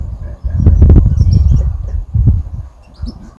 Tuquito Gris (Empidonomus aurantioatrocristatus)
Nombre en inglés: Crowned Slaty Flycatcher
Condición: Silvestre
Certeza: Observada, Vocalización Grabada
Tuquito-gris.mp3